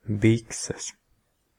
Ääntäminen
UK : IPA : /ˈtɹaʊ.zəz/